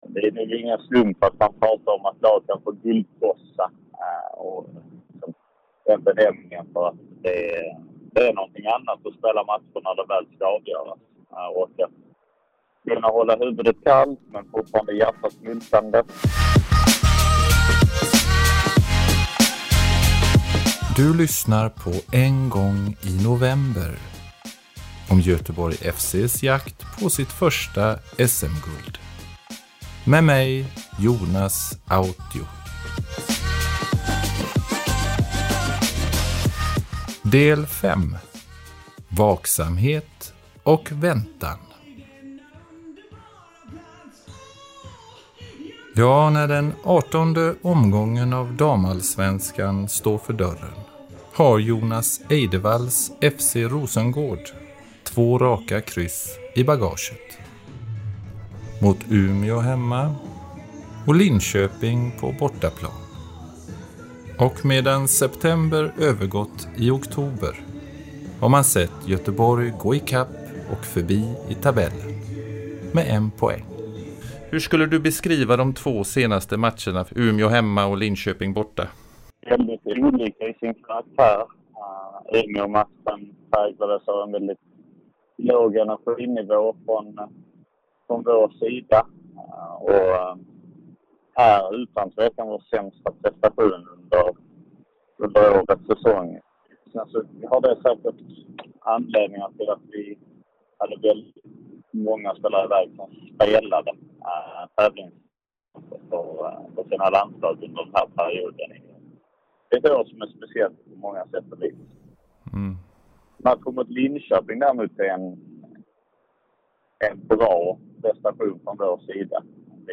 Intervjuer